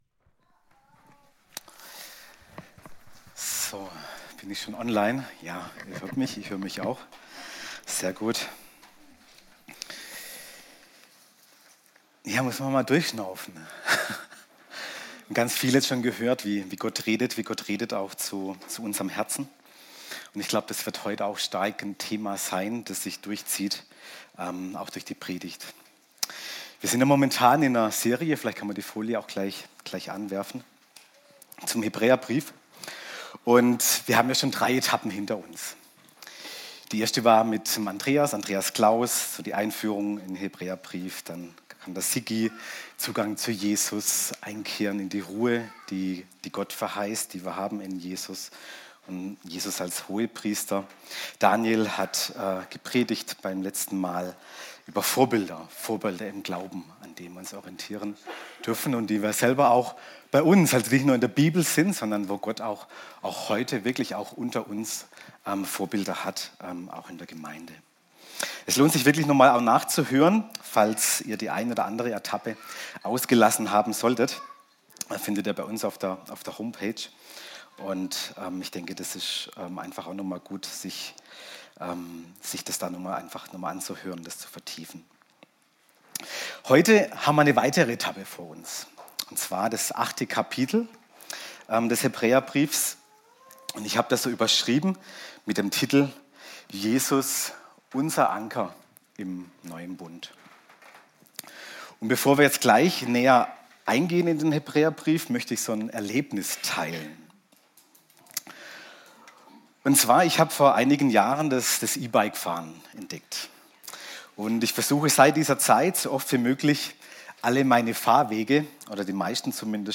Predigt – bmg Leonberg